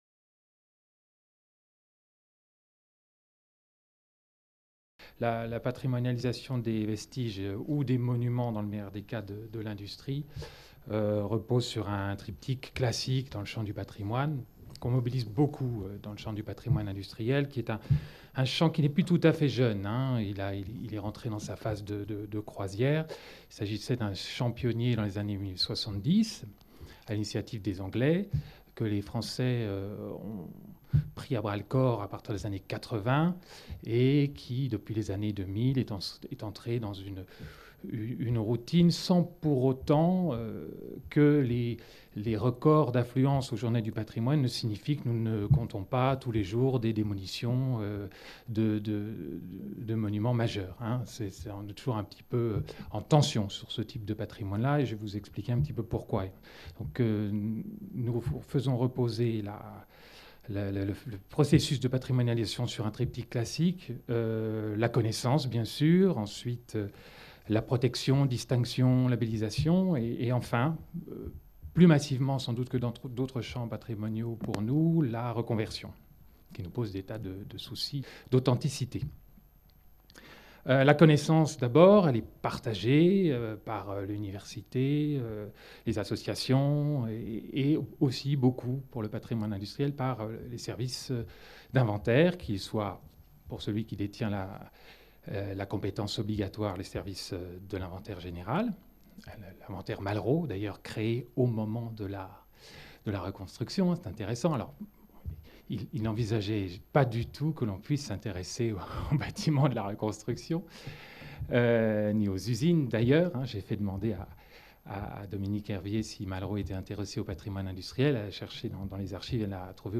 Cette communication a été filmée lors d'une table ronde programmée dans le cadre du colloque Art, industrie et société au temps de la Reconstruction qui s’est tenu au Centre Culturel International de Cerisy du 5 au 12 juin 2019